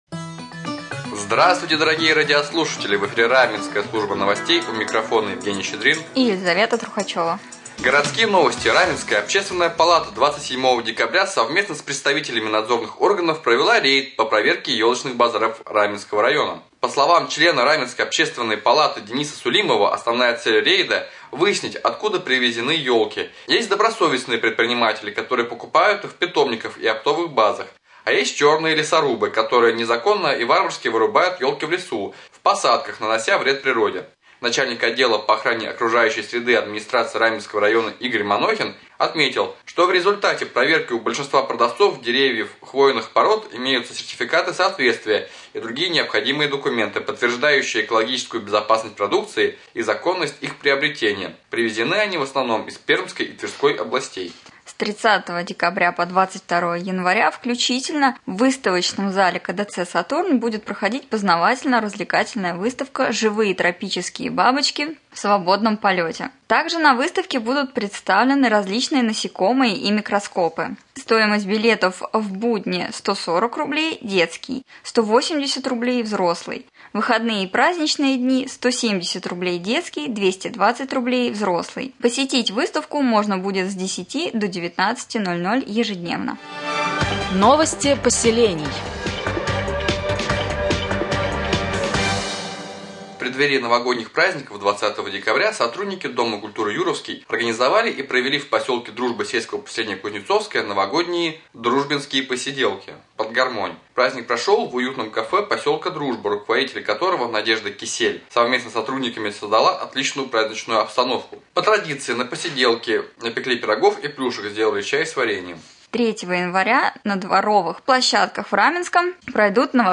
1. Новости